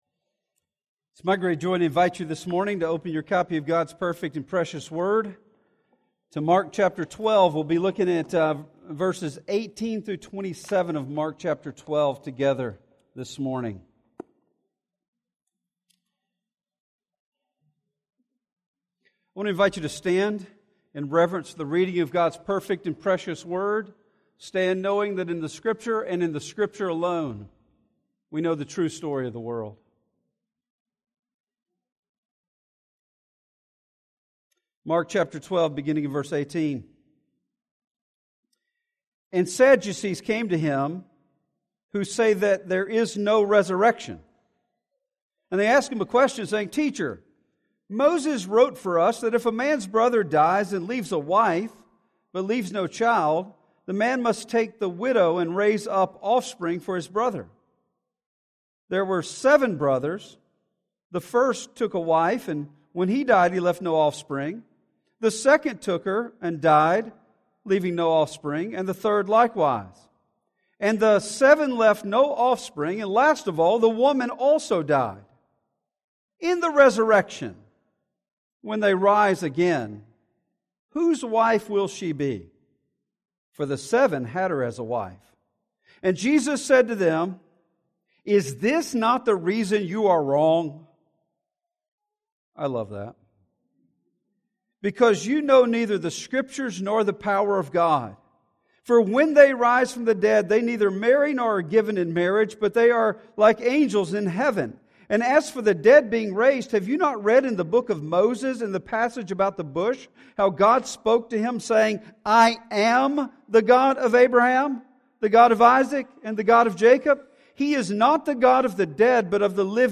In this sermon in the "Confronting Jesus" series we see that God eternal plans pale in comparison to what we could even imagine.